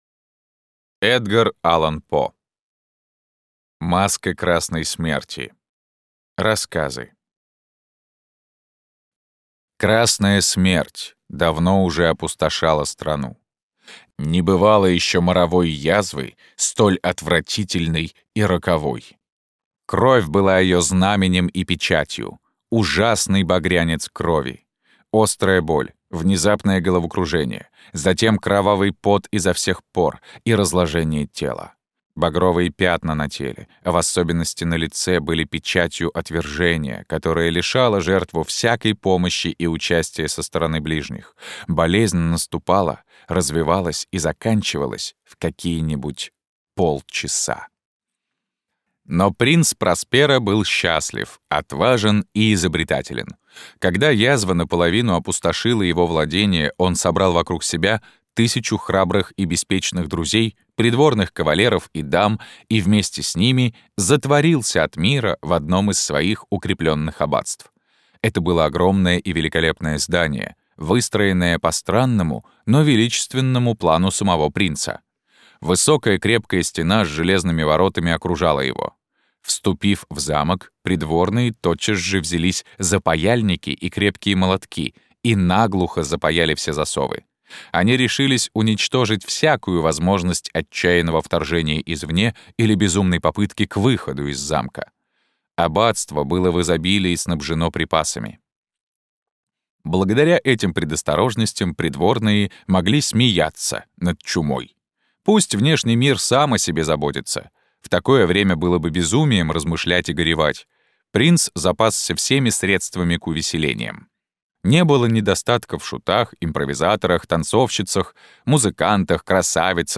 Аудиокнига Маска Красной Смерти. Рассказы | Библиотека аудиокниг